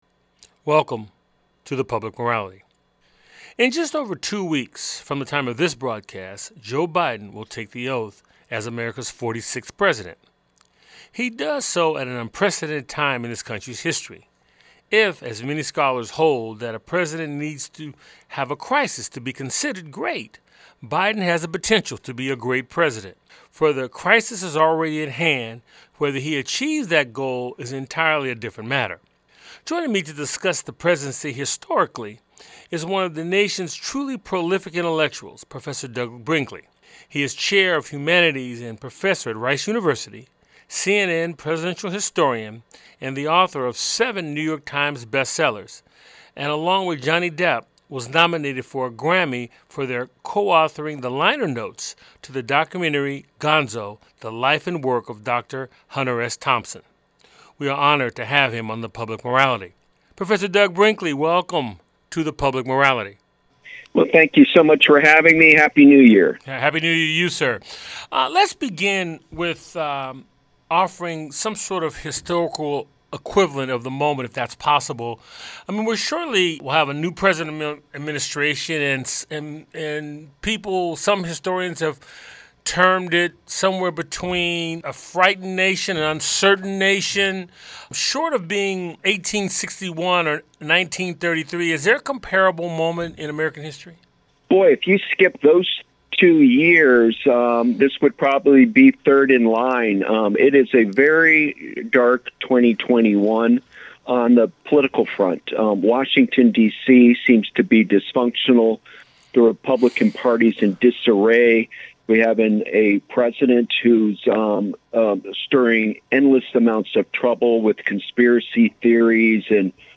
Guests on this show are Douglas Brinkley, Historian and Author.
The show airs on 90.5FM WSNC and through our Website streaming Tuesdays at 7:00p.